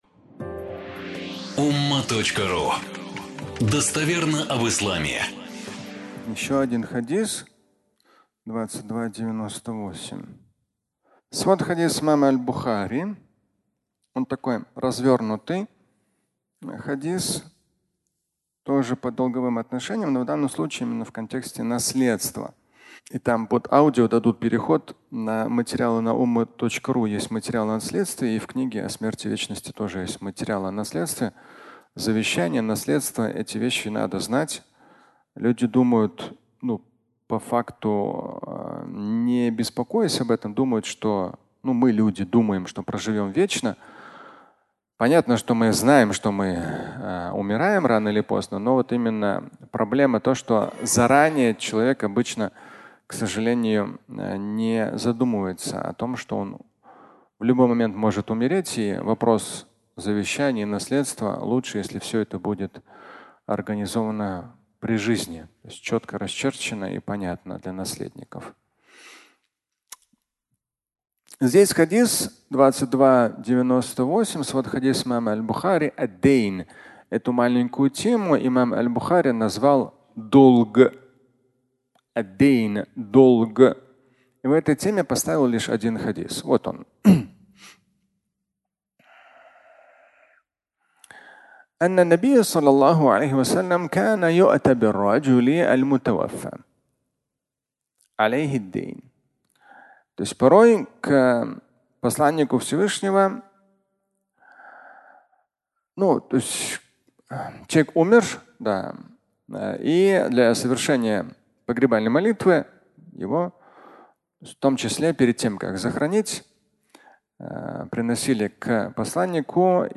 Наследство (аудиолекция)
Пятничная проповедь